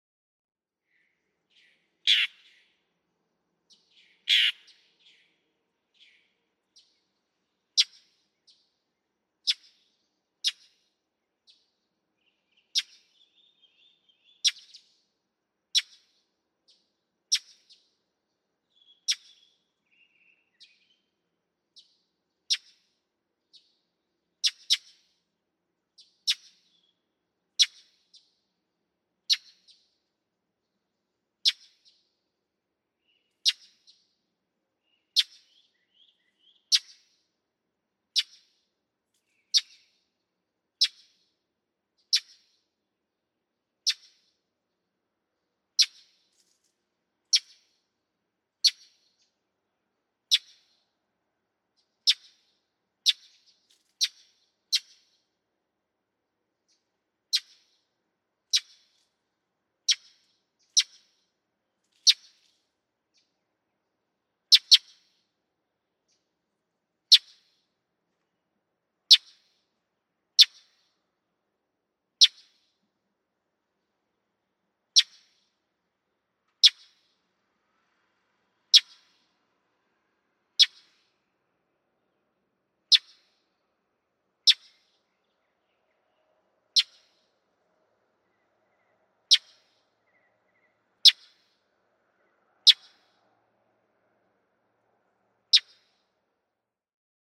Northern mockingbird
Awaking mockingbirds sound like angry mockingbirds, snarling and growling from their overnight roosts.
Naples, Florida.
463_Northern_Mockingbird.mp3